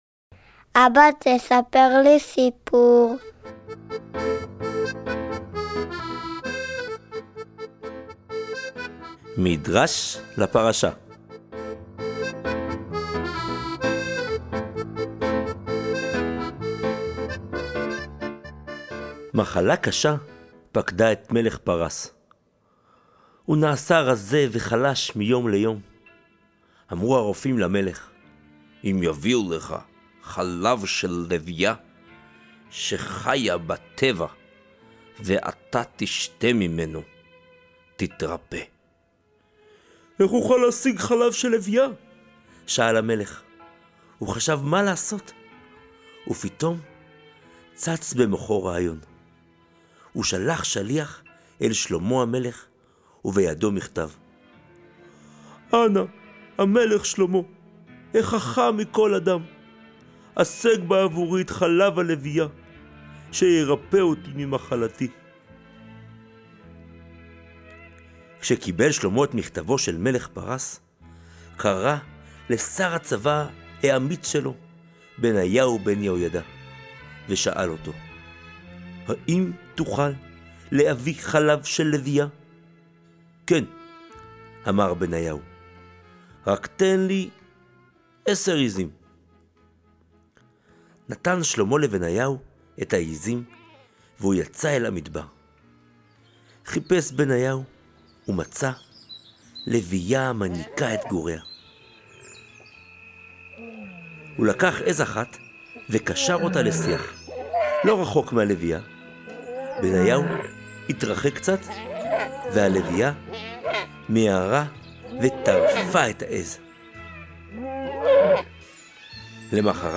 הוקלט באולפני שאמיל